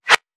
weapon_bullet_flyby_15.wav